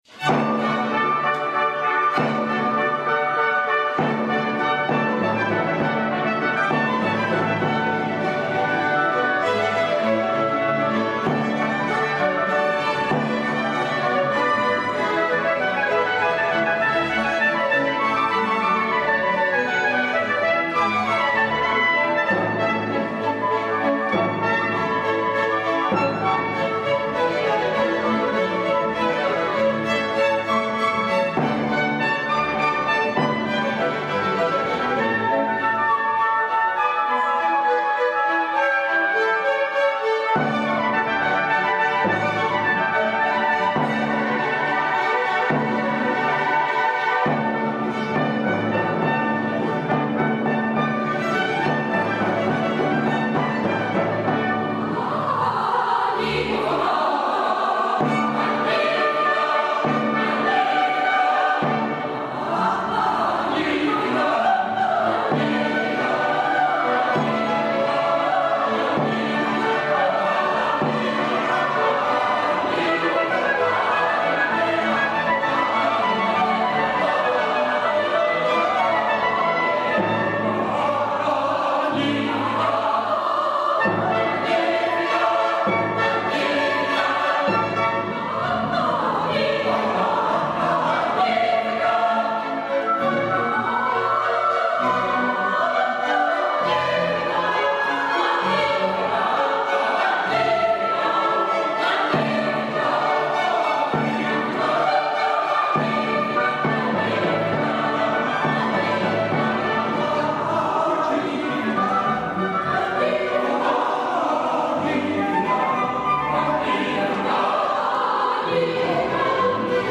GenereCori